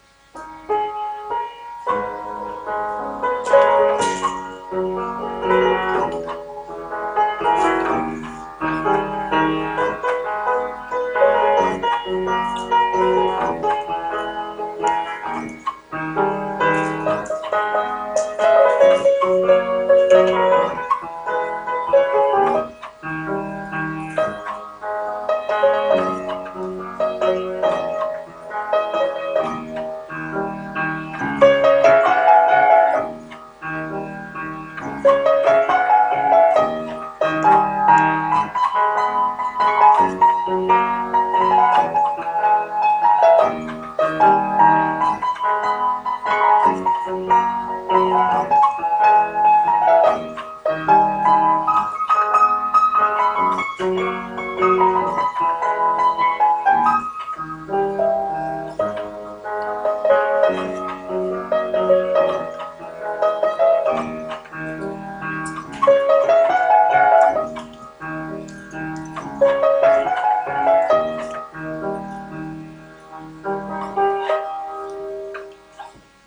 Actually, it's not a song, there are no words.